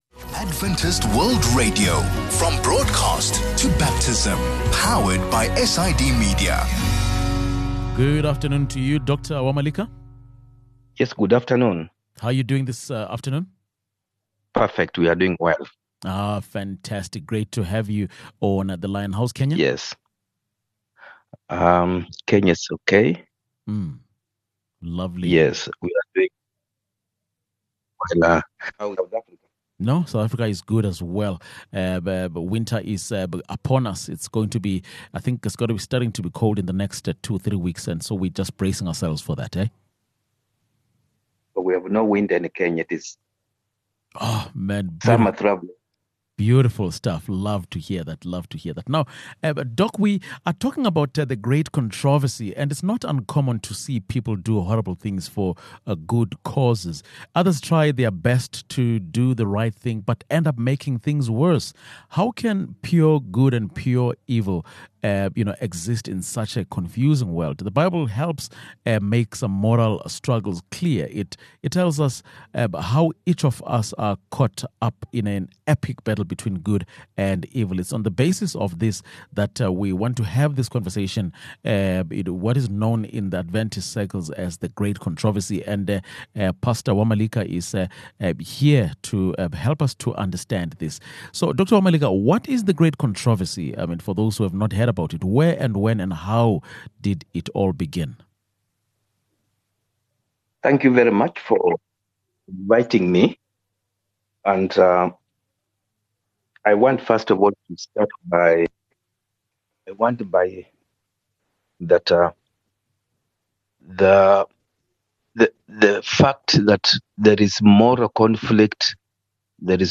It tells how each of us are caught in an epic battle between good and evil. It is on this basis that we have this conversation about what is known in SDA circles as, The Great Controversy.